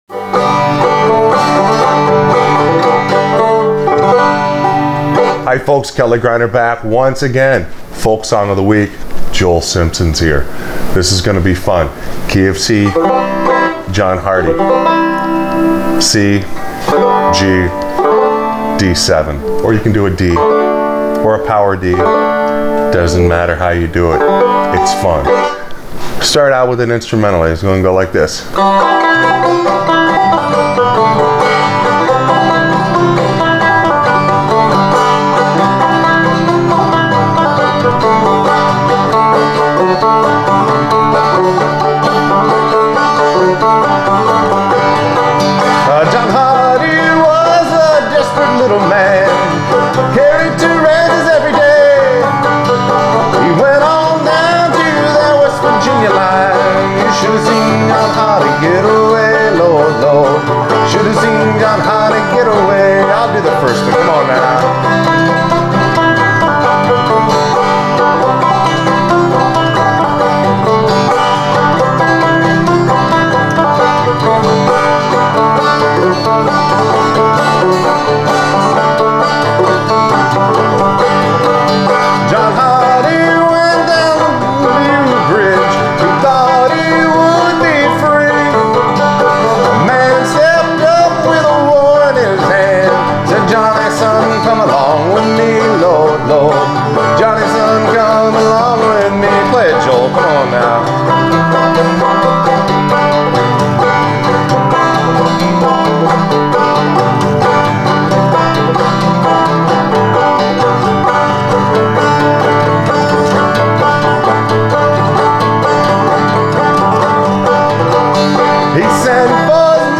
Folk Song Of The Week – John Hardy – Accompaniment for Frailing Banjo
I love playing frailing banjo to John Hardy!